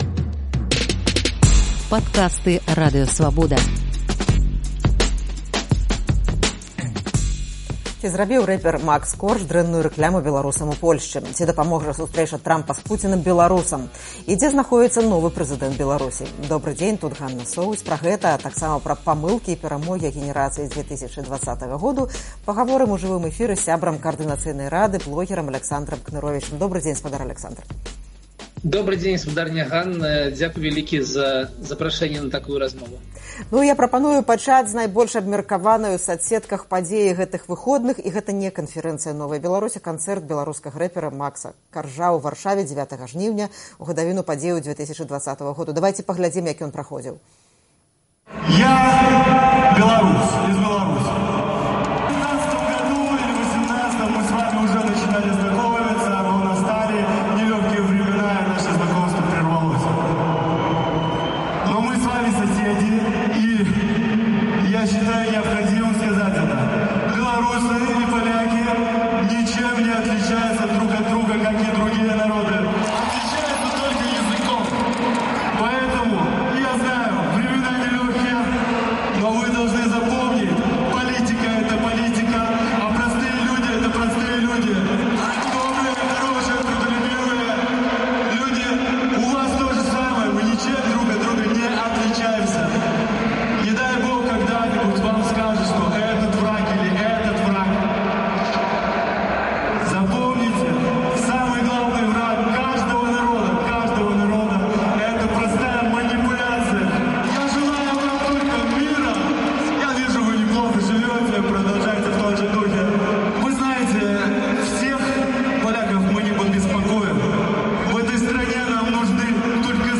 Ці дадуць акно магчымасьцяў для беларусаў сустрэчы Трампа з Пуціным і Зяленскім? Пра гэта ды іншае ў жывым эфіры «ПіКа Свабоды» пагаворым з сябрам Каардынацыйнай рады